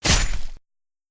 Melee Weapon Attack 11.wav